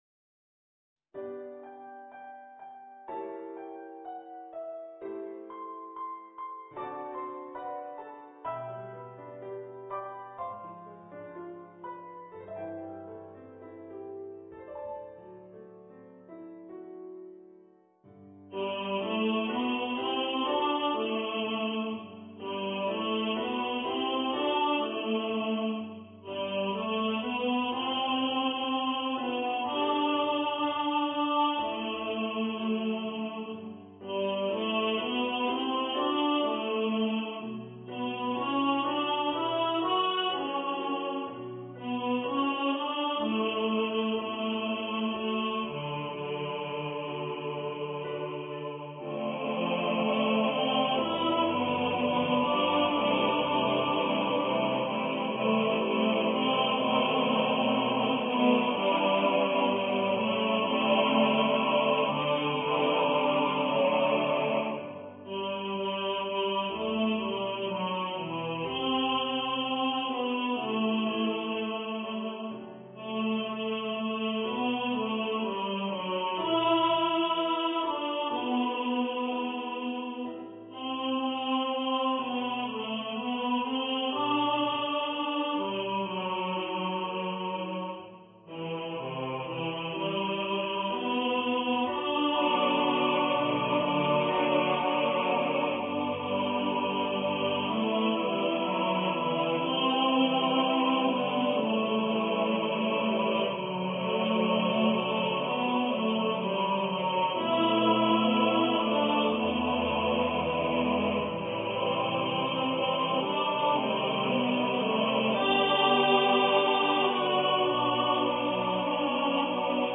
for male voice choir
Choir - Male voices